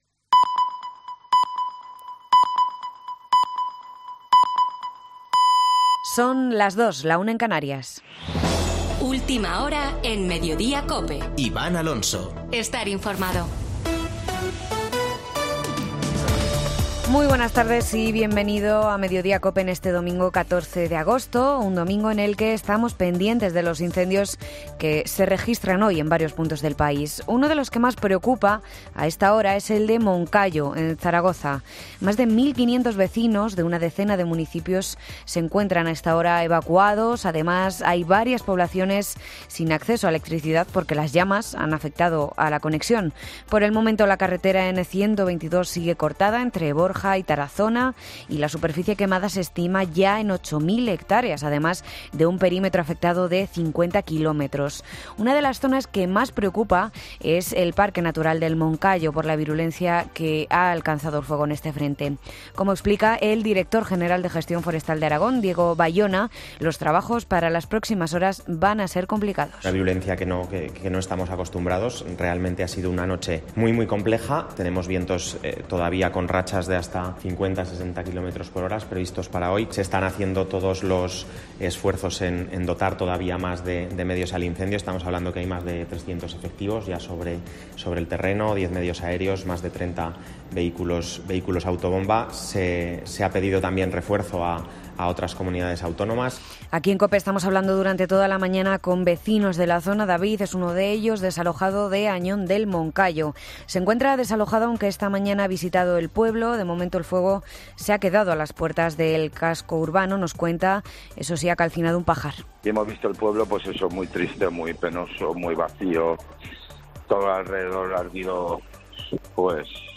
Boletín de noticias de COPE del 14 de agosto de 2022 a las 14.00 horas